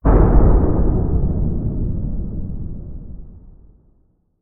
Commotion17.ogg